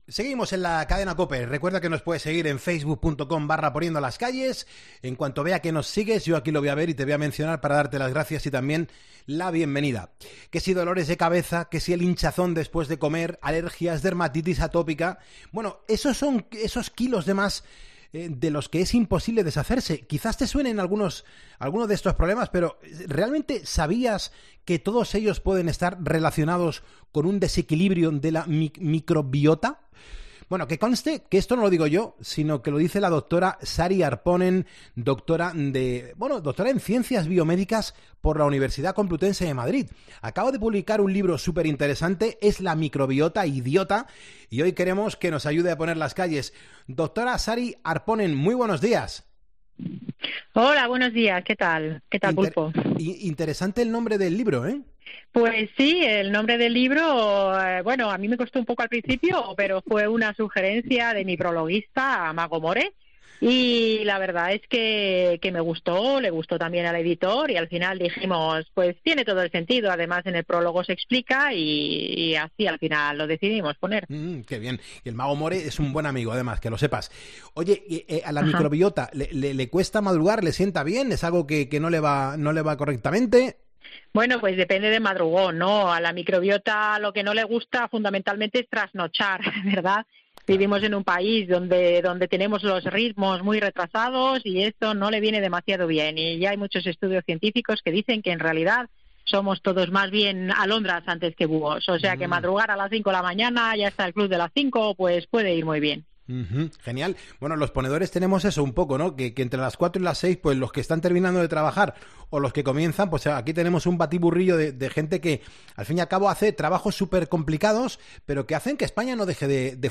Entrevistas en Poniendo las calles ¿Qué son las microbiotas y por qué deberías saberlo?